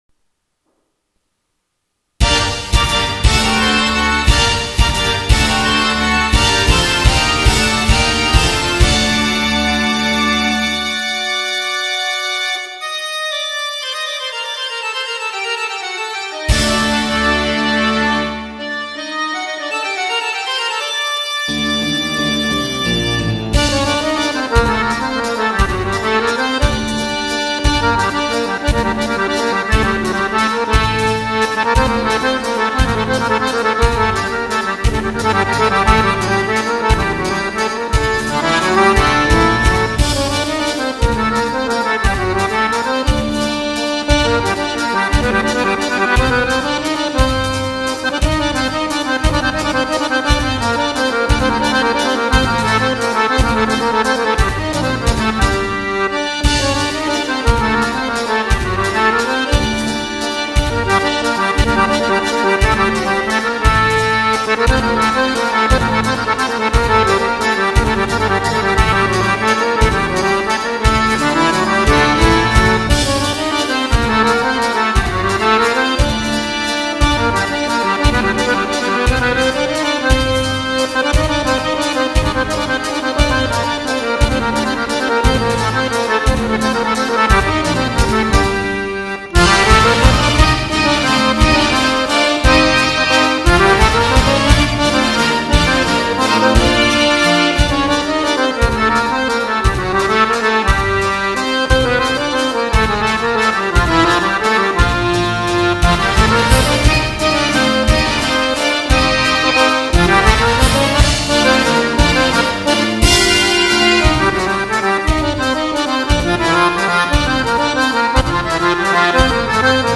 In questa sezione potete ascoltare qualche interpretazione registrata in modalità casareccia, con basi orchestrali composte al computer.